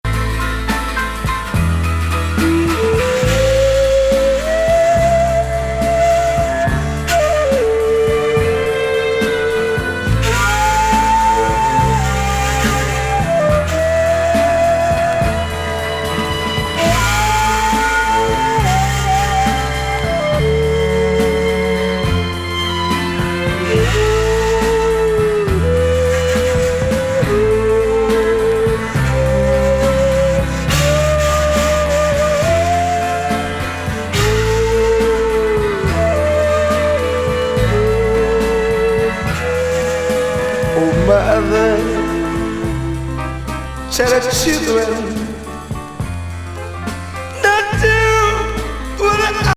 間奏の尺八もニクイかっこよいヘビー・フォーキー・グルーヴ